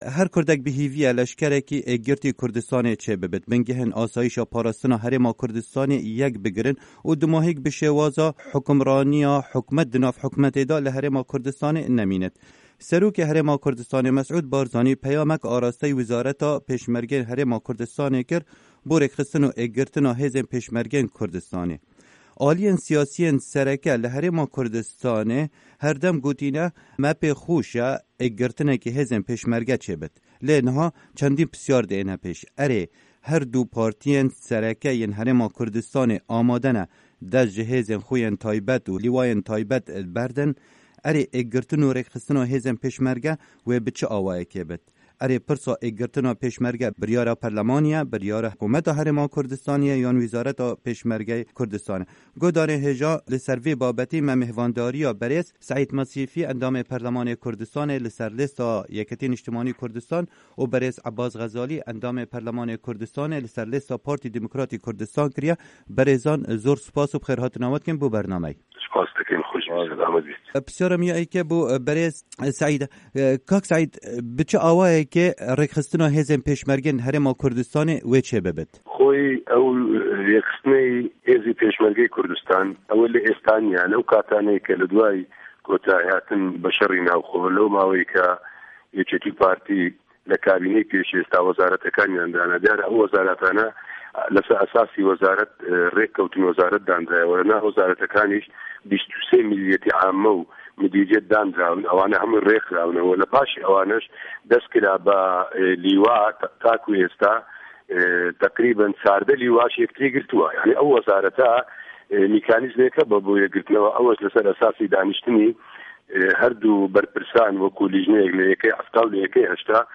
مـێزگرد: ئێکگرتنا هێزێن پێشمه‌رگه‌